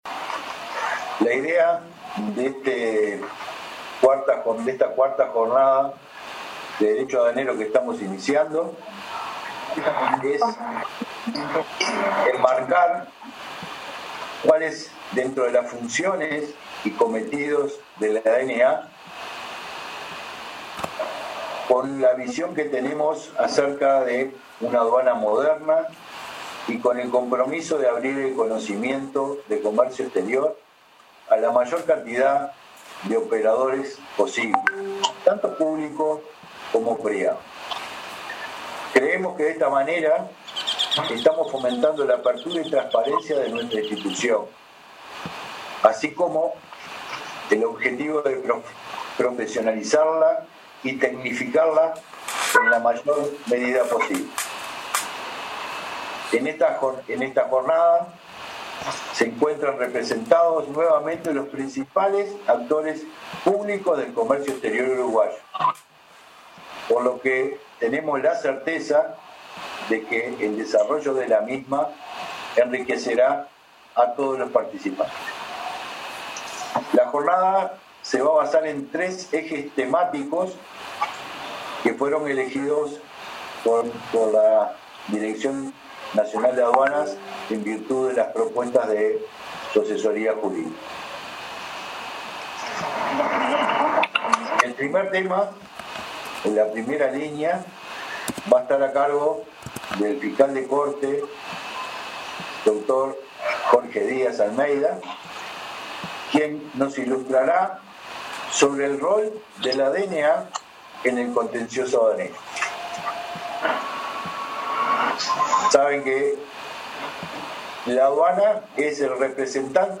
El director nacional de Aduanas, Jaime Borgiani, abrió este jueves, vía zoom, la cuarta Jornada de Derecho Aduanero que organiza esa dirección. En ese marco, el jerarca resaltó la importancia de enmarcar a la mayor cantidad de operadores públicos y privados en este intercambio y dijo que se busca consolidar una aduana moderna, profesionalizada y tecnificada.